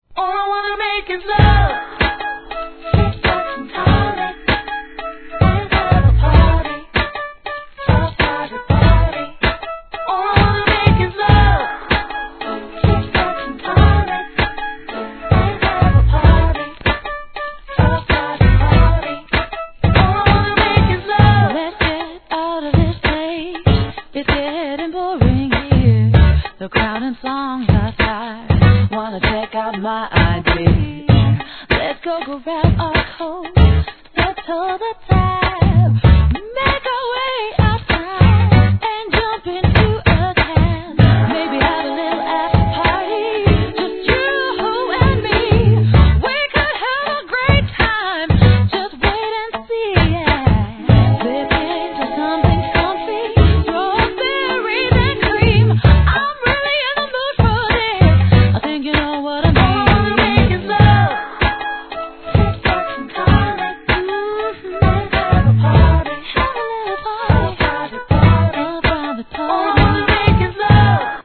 HIP HOP/R&B
可愛らしいヴォーカルで歌うR&B!